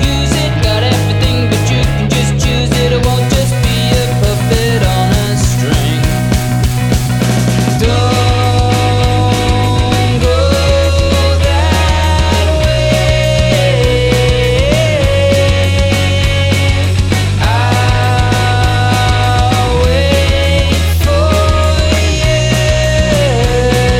Vocal Version Rock 3:54 Buy £1.50